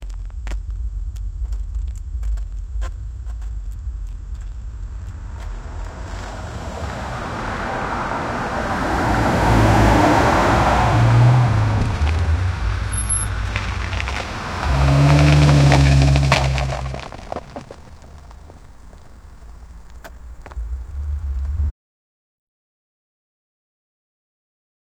A car pulling into a gravel unpaved road and braking
a-car-pulling-into-a-q7qztch5.wav